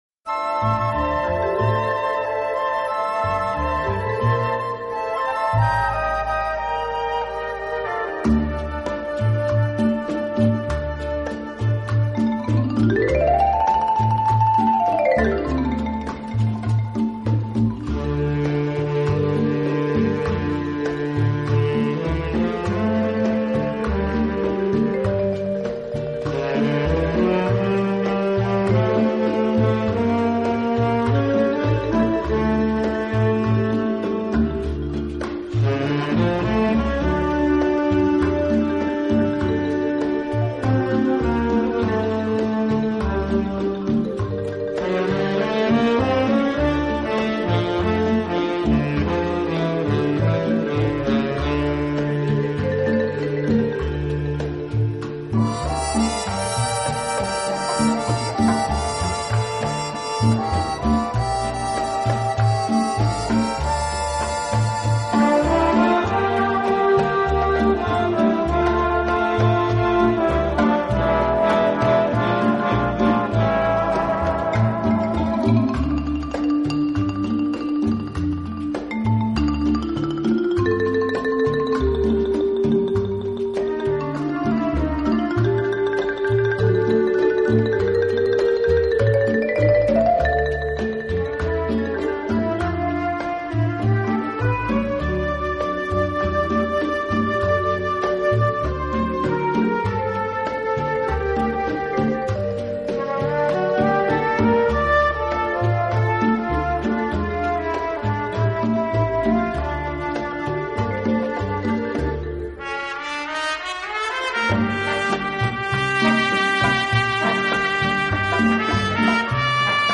【拉丁爵士】